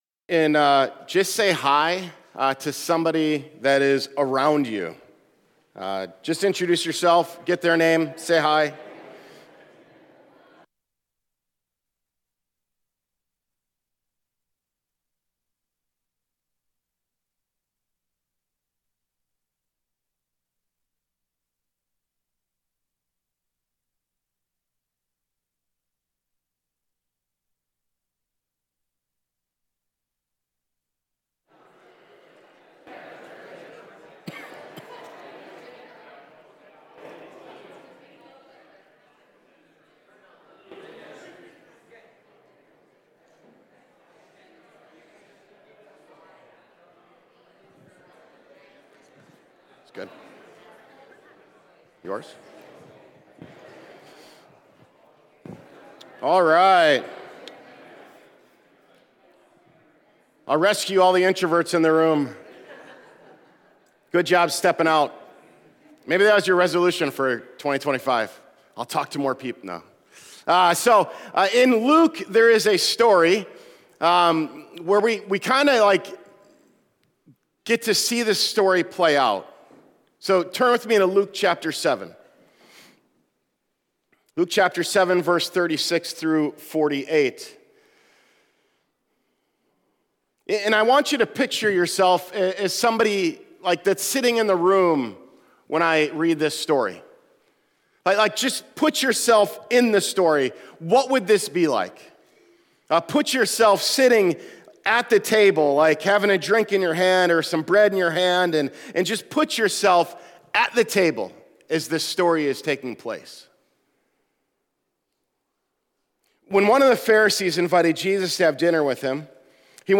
Sermons by Renew Church